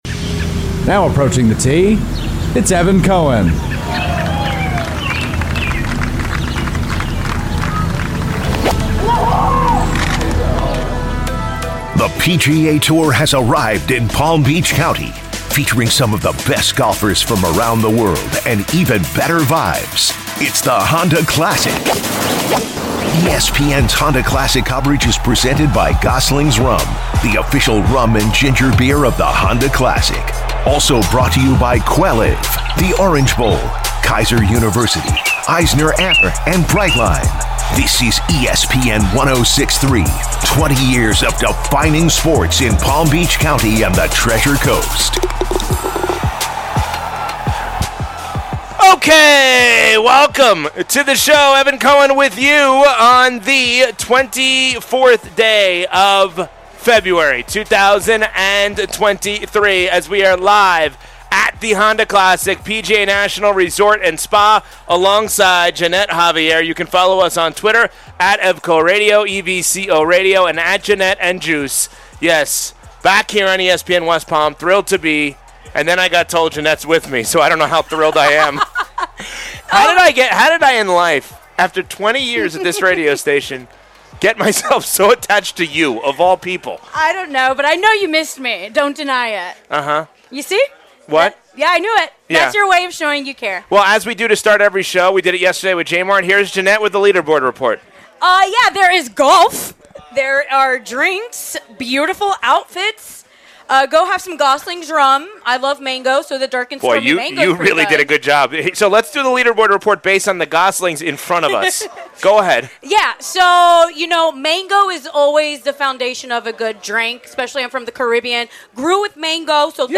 Commercial-free from 5p-6p weekdays on ESPN West Palm.